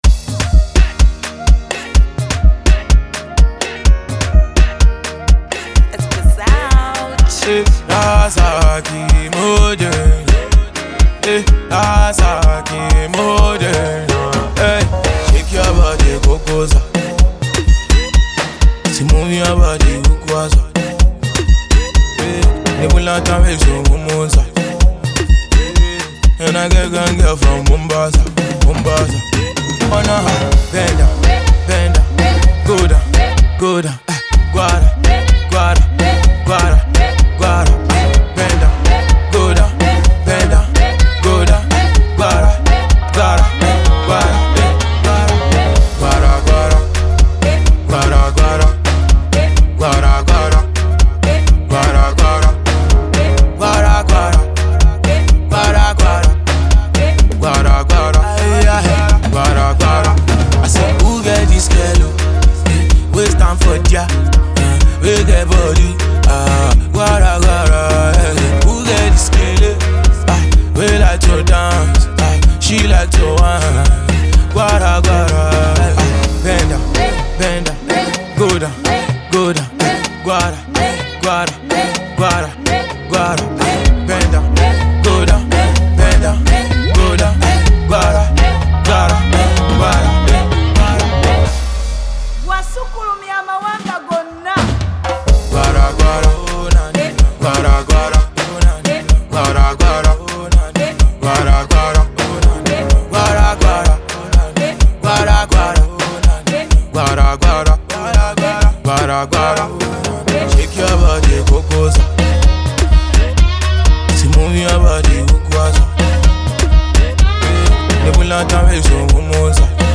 potential December groove
dance single